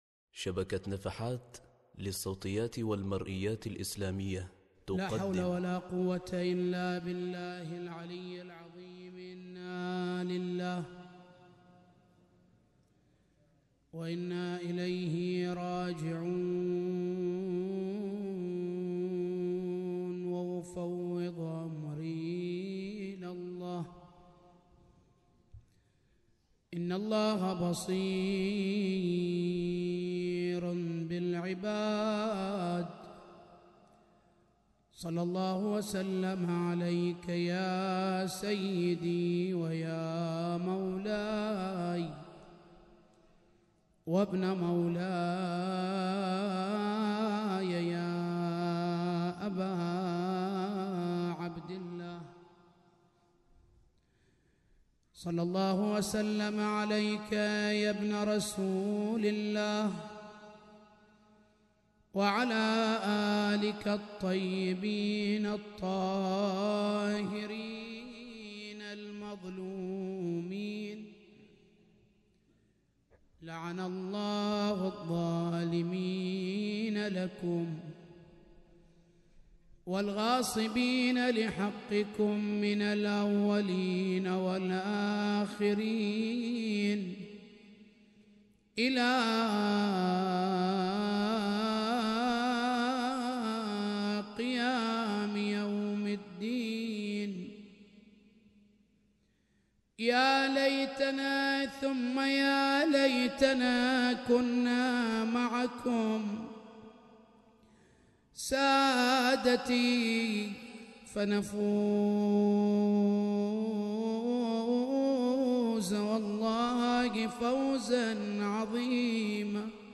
الليلة 7 محرم 1436هـ – عنوان المحاضرة: اثر الايمان والولاء لأهل البيت ع